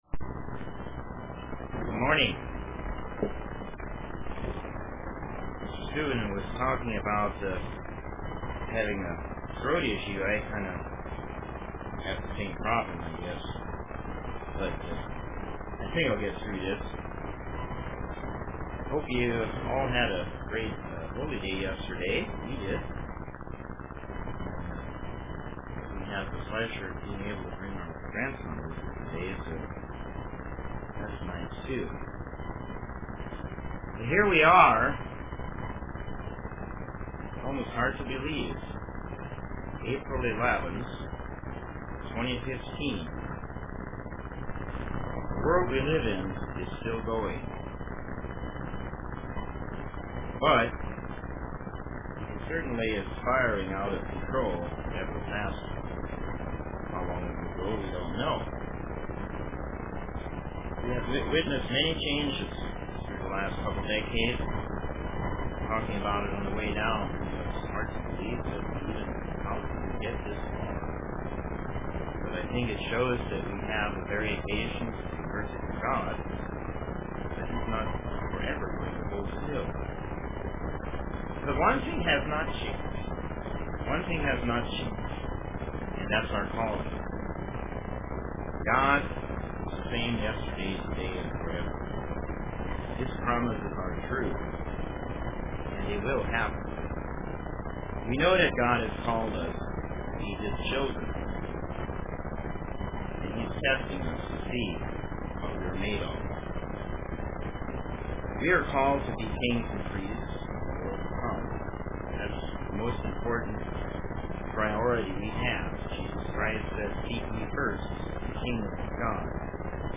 Print Character UCG Sermon Studying the bible?